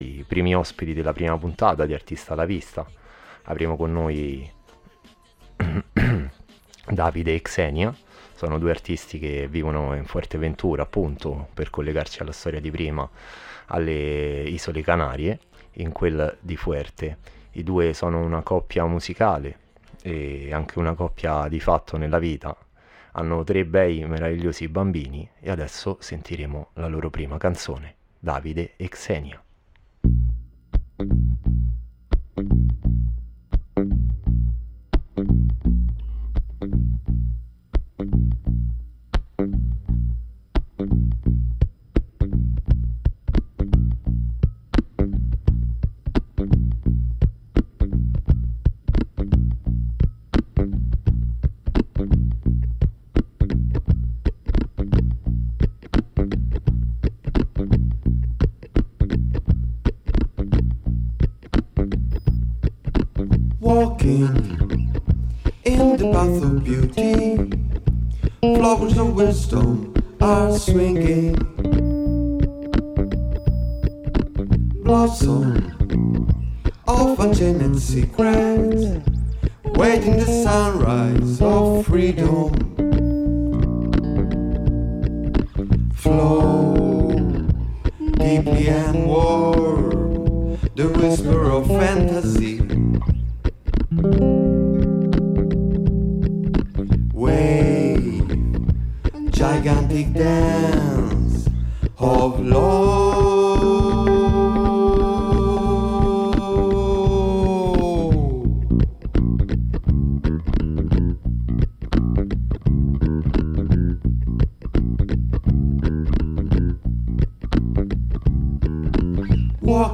Gustatevi musica e racconti!